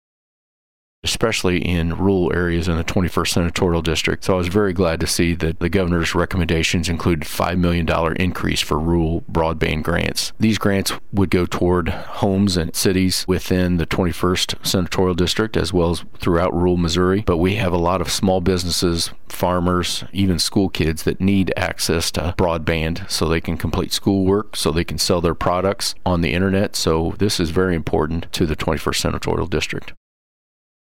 3. Senator Hoskins also says the Missouri Senate Appropriations Committee has also started to meet.